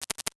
NOTIFICATION_Subtle_02_mono.wav